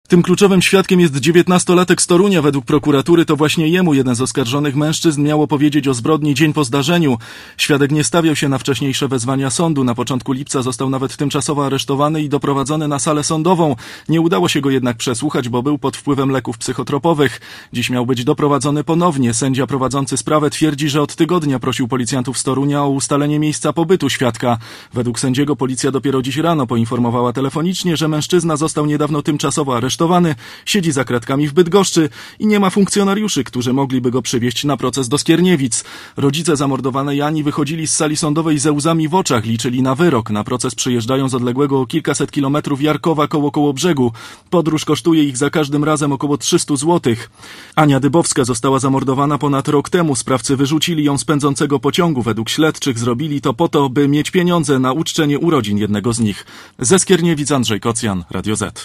Komentarz reportera Radia ZET